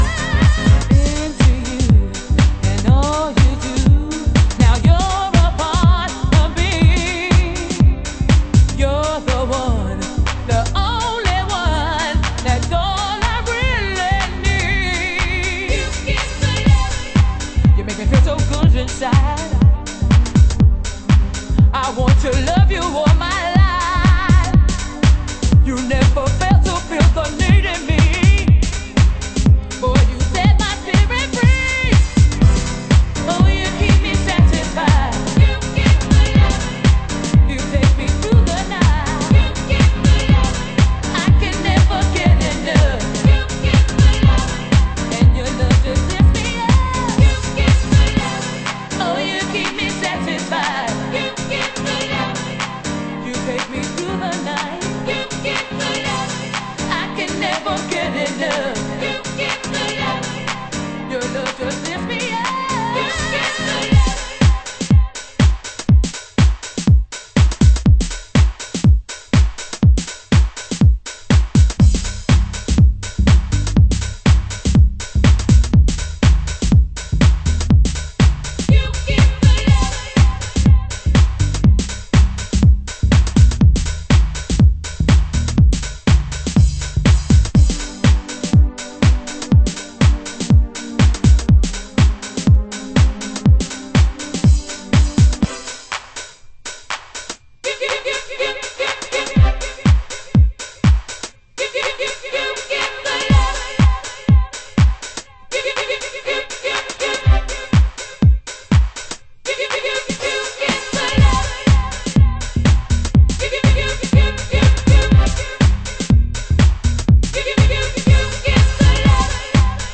★DEEP HOUSE 歌 WHITE